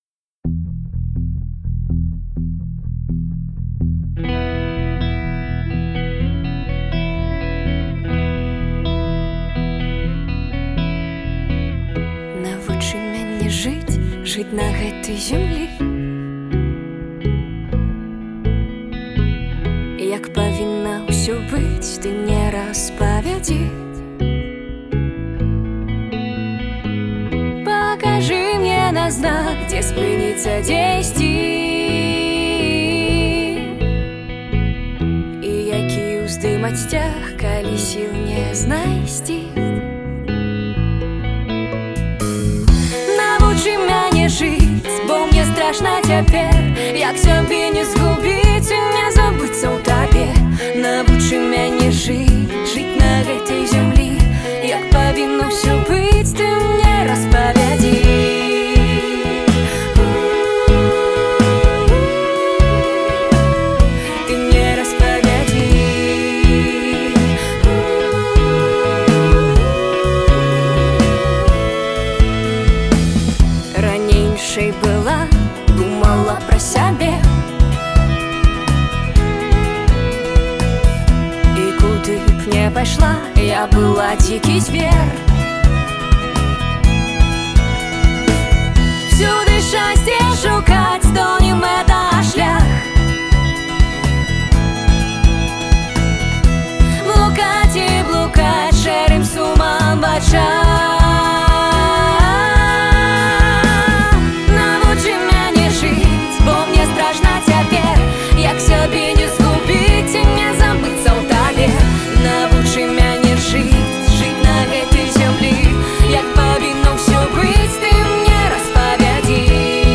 студыйную вэрсію песьні